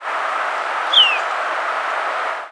Orchard Oriole Icterus spurius
Flight call description A descending, burry "yeehr".  In short flights also gives a low, husky "chhk", often doubled, and rarely a descending, whistled "heoo".